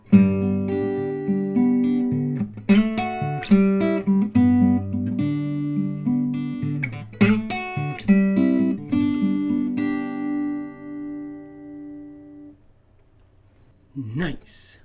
Through this mic, the acoustic guitar sounds like this: